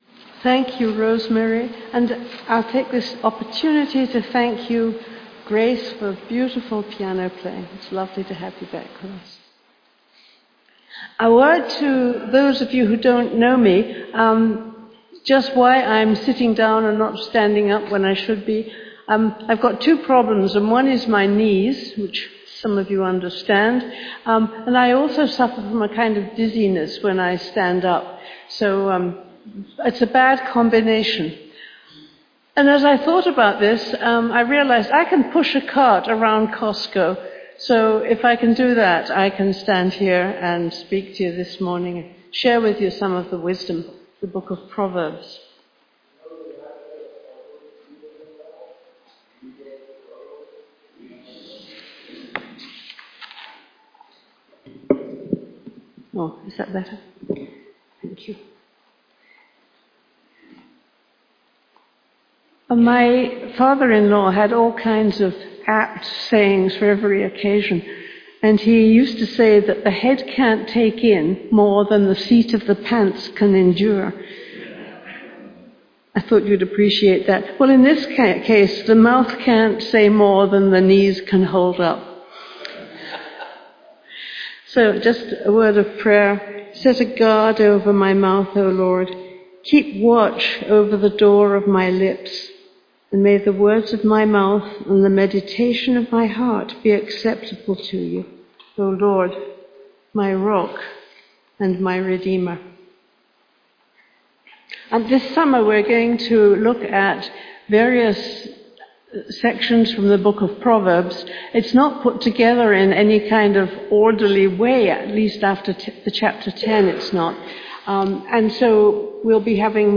2025 Sermon July 13 2025